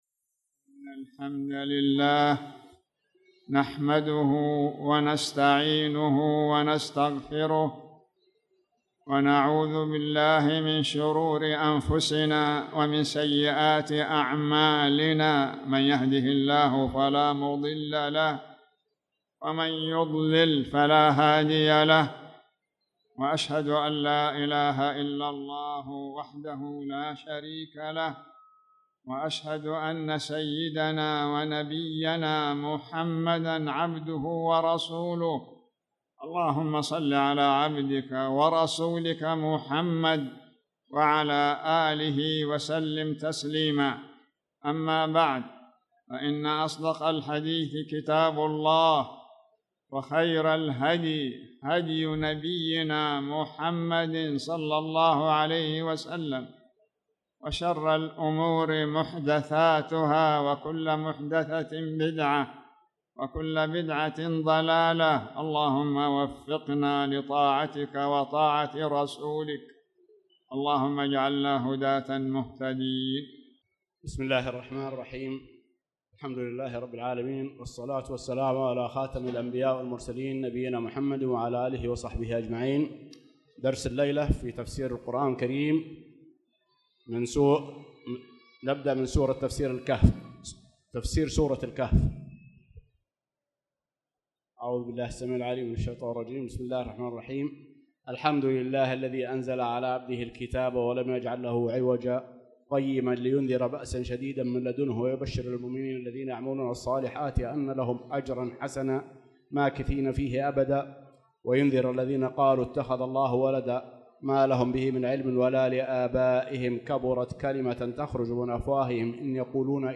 تاريخ النشر ١٣ شوال ١٤٣٧ هـ المكان: المسجد الحرام الشيخ